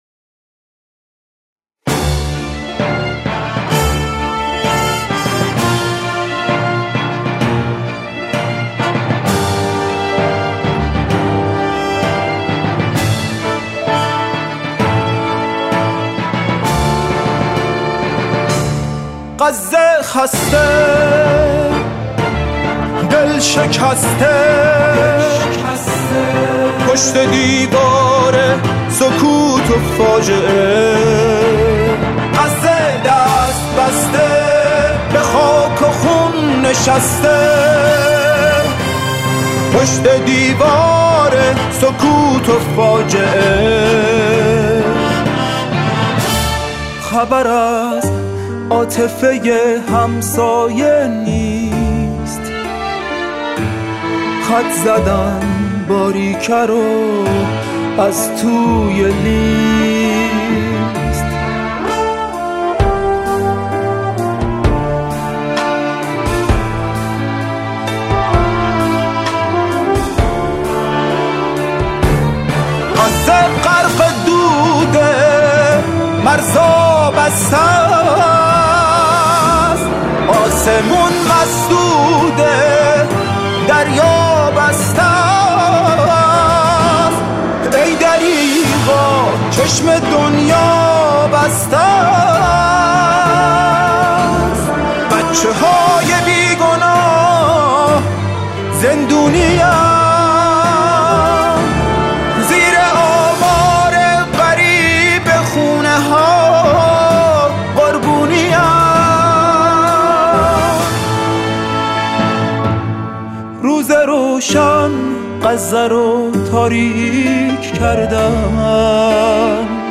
نوازندگان این قطعه ویلنسل
ویلن ۱
ویلن آلتو
هورن
ترومپت
کنترباس